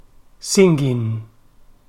/sɪŋgɪn
It’s equally wrong and, although some native speakers do it too, it is generally considered bad pronunciation.